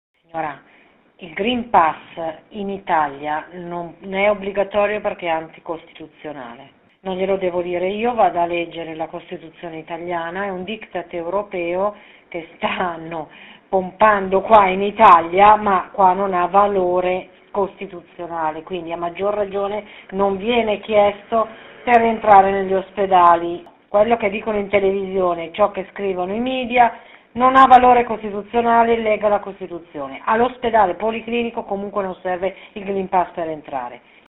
Radio Popolare ha provato a vedere com’è la situazione chiamando alcuni ospedali lombardi, questo è quello che abbiamo scoperto.